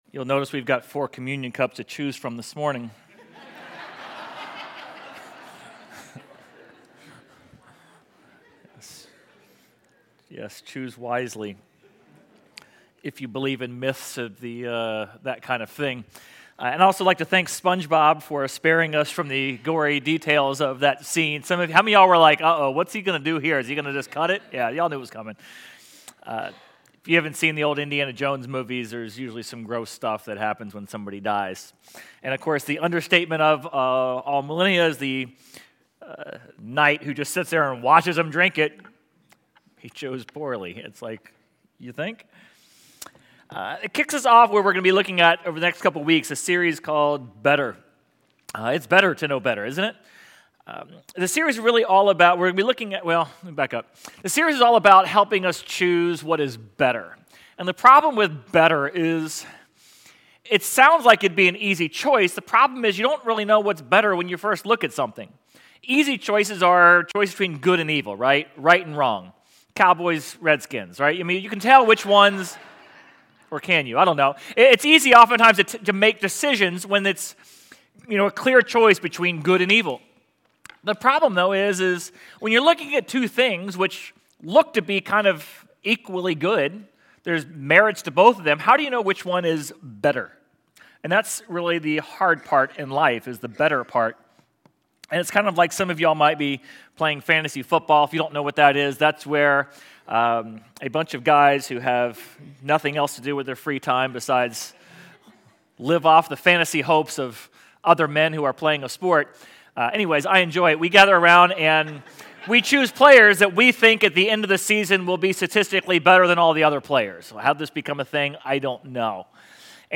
Sermon Audio/Video | Essential Church